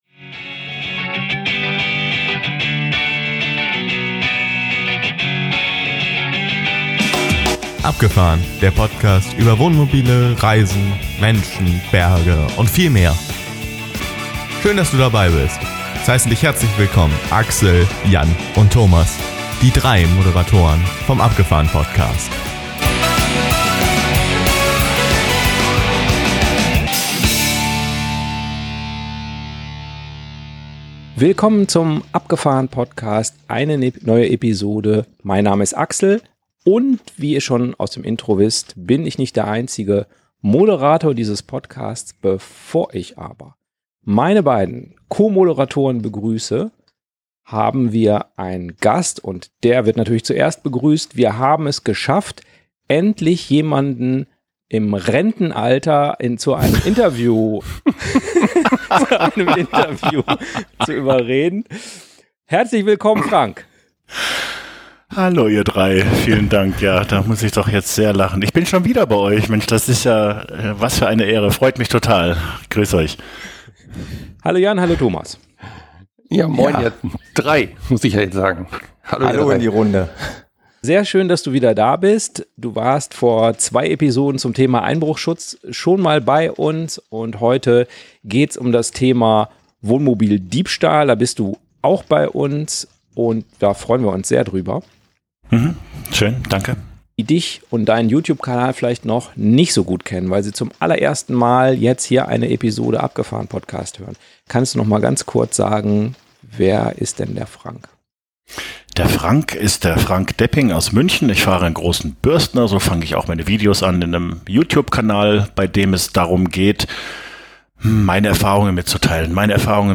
Wie kann man ein gestohlenes Wohnmobil wiederfinden? Freut euch auf echte Fakten vom Landeskriminalamt Niedersachsen und ein Gespräch unter Freunden über passive Schutzmöglichkeiten und aktive, wie elektronische Wegfahrsperren oder GPS-Tracker.